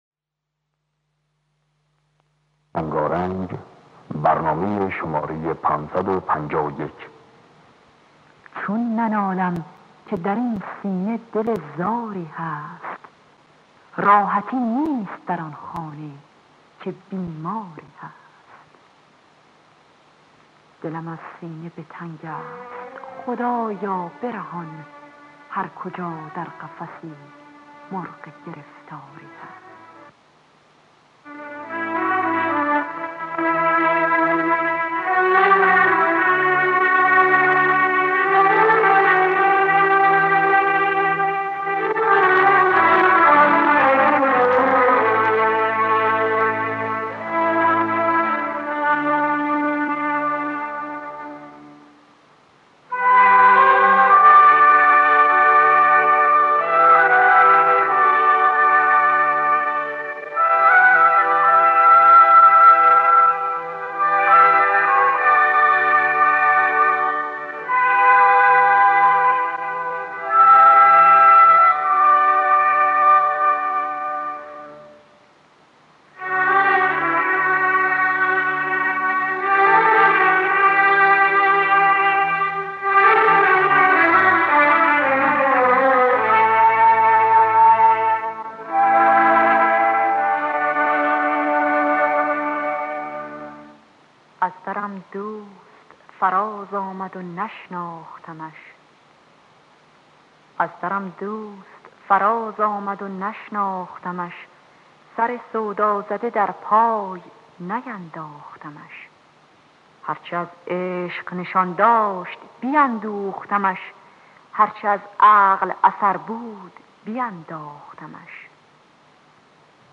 در دستگاه بیات ترک